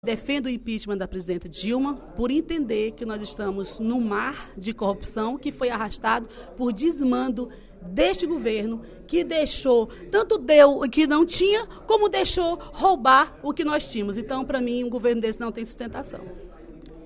A deputada Dra. Silvana (PMDB) voltou a defender, no segundo expediente da sessão plenária desta sexta-feira (18/09), o impeachment da presidente Dilma Rousseff. Para a parlamentar, a presidente é culpada pelas atuais irregularidades do País, já que estas apareceram em seu mandato.